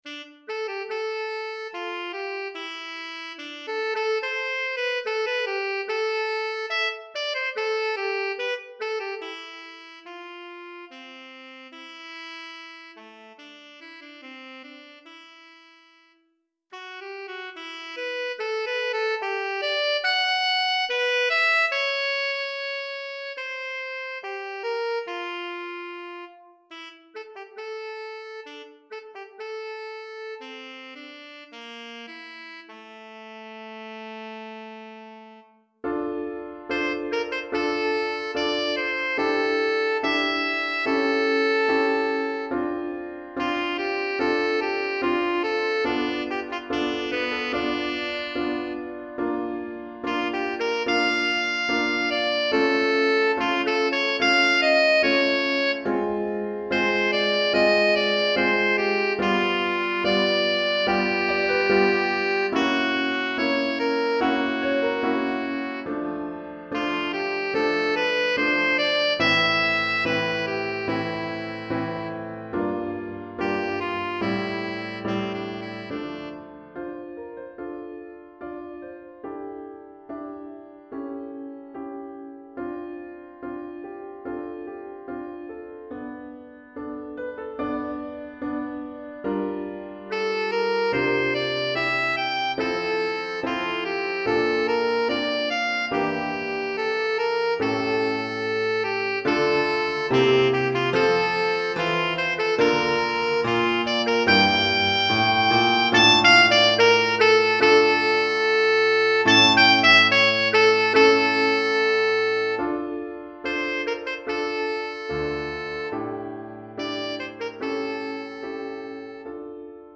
Pour saxophone alto et piano DEGRE DEBUT DE CYCLE 2